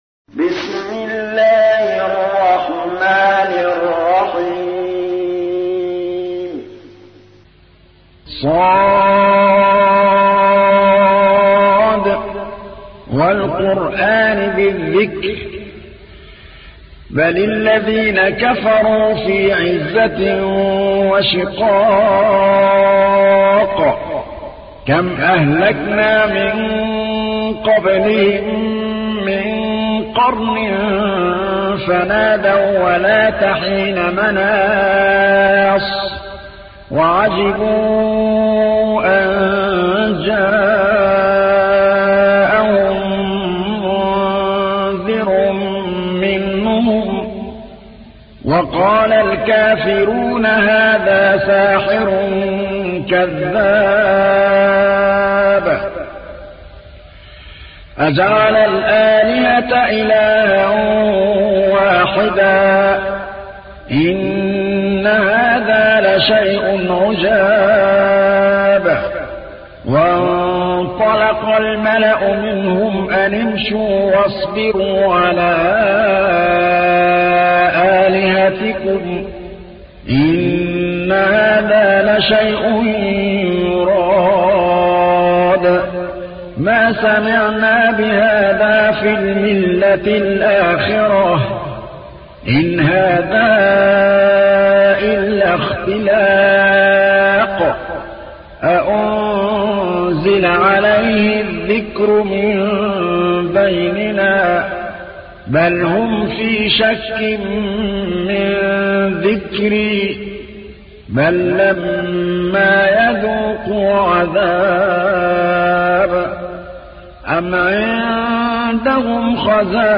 تحميل : 38. سورة ص / القارئ محمود الطبلاوي / القرآن الكريم / موقع يا حسين